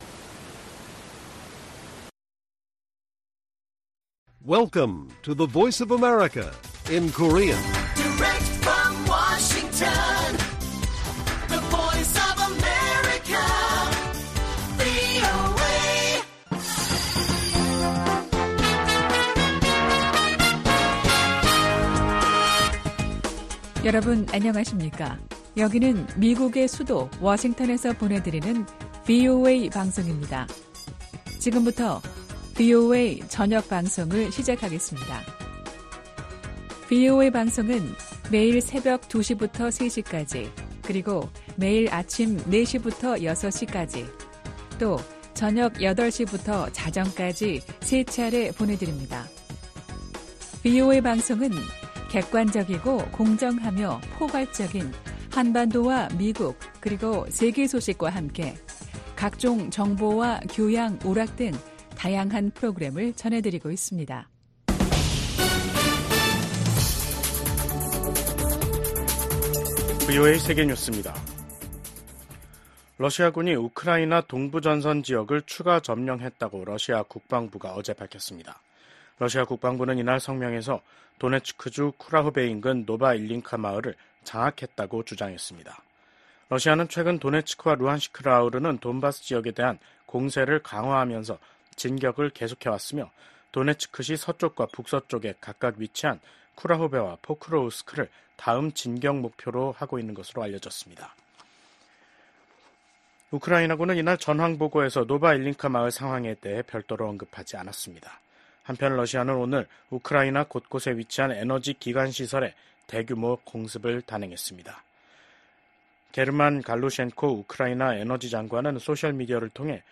VOA 한국어 간판 뉴스 프로그램 '뉴스 투데이', 2024년 11월 28일 1부 방송입니다. 우크라이나 사태를 주제로 열린 유엔 안보리 회의에서 미국 대표는 북한을 향해 러시아 파병이 사실이냐고 단도직입적으로 물었고, 북한 대표는 북러 조약 의무를 충실히 이행하고 있다며 이를 우회적으로 시인했습니다. 러시아와 전쟁 중인 우크라이나 특사단이 윤석열 한국 대통령 등을 만났지만 한국 측은 무기 지원에 신중한 입장을 보였습니다.